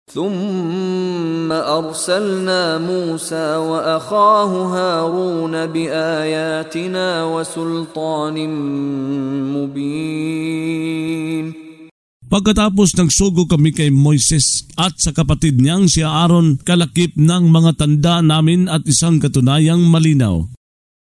Pagbabasa ng audio sa Filipino (Tagalog) ng mga kahulugan ng Surah Al-Mu'minun ( Ang Mga Sumasampalataya ) na hinati sa mga taludtod, na sinasabayan ng pagbigkas ng reciter na si Mishari bin Rashid Al-Afasy. Ang paglilinaw sa tagumpay ng mga mananampalataya atpagkalugi ng mga tagatangging sumampalataya.